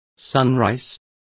Complete with pronunciation of the translation of sunrise.